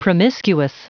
Prononciation du mot promiscuous en anglais (fichier audio)
Prononciation du mot : promiscuous